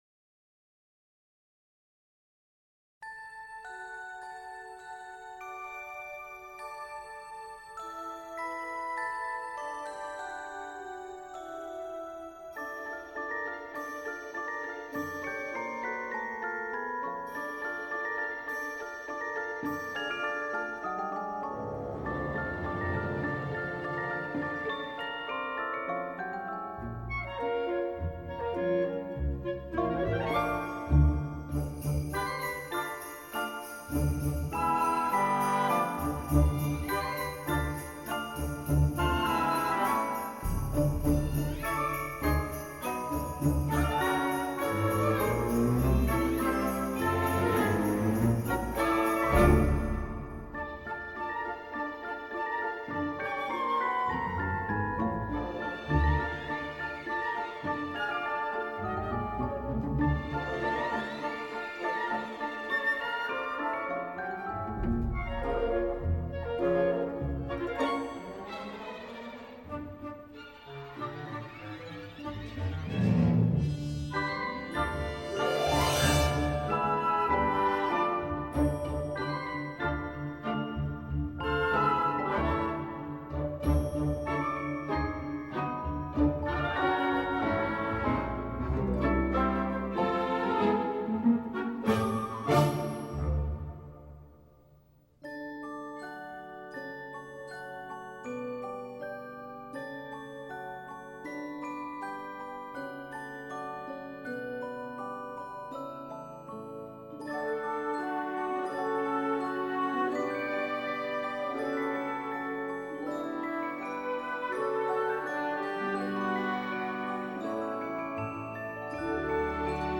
Soundtrack, Holiday